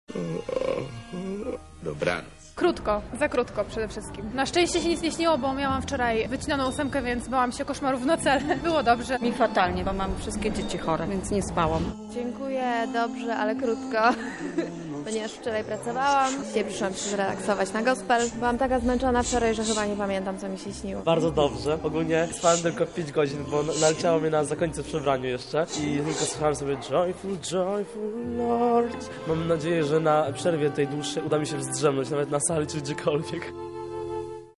Z tej okazji zapytaliśmy uczestnikó warsztatów wokalnych Wiosna Gospel, jak im się dzisiaj spało